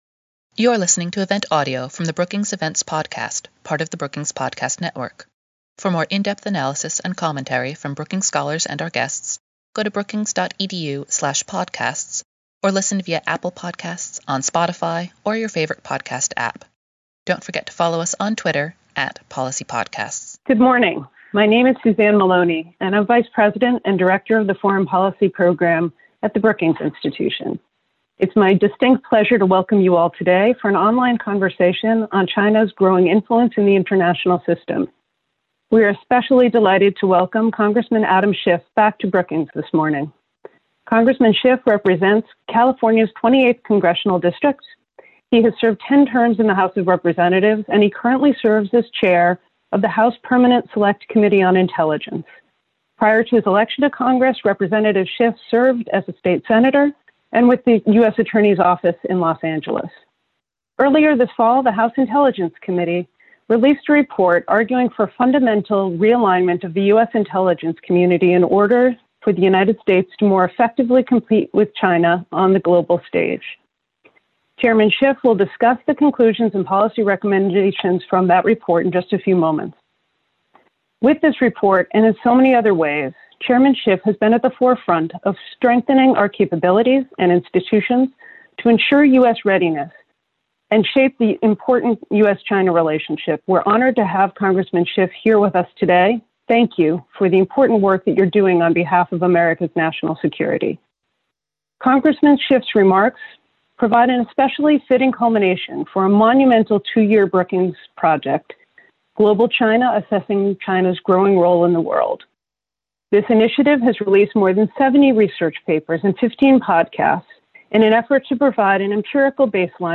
The event featureed a keynote address from Representative Adam Schiff.